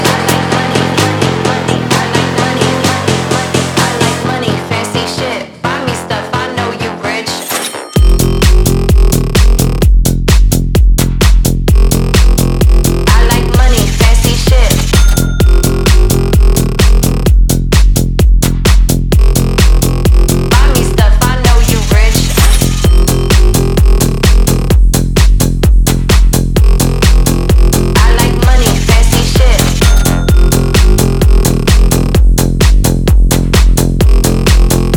Жанр: Танцевальные / Хаус
House, Dance